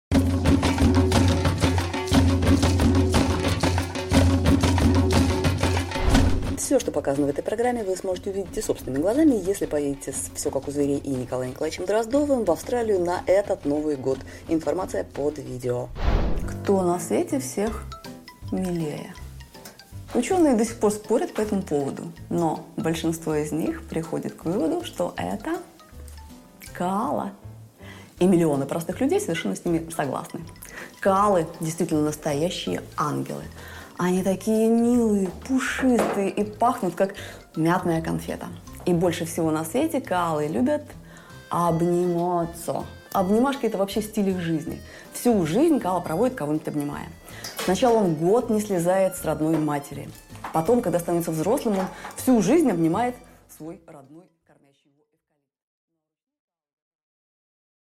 Аудиокнига Коала: куда уходит мозг | Библиотека аудиокниг